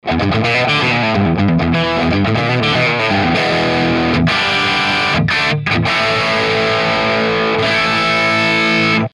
выдрал файлы и ожидаемо кортекс живому ампу сливает прилично.
но по звуку пока скромно довольно, с 5150 и подобными наверняка будет ближе